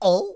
traf_damage7.wav